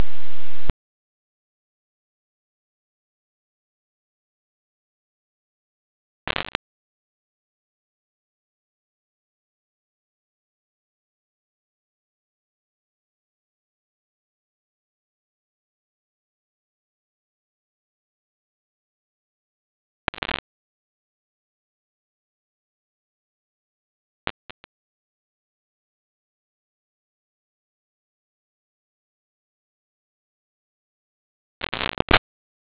pulsar sound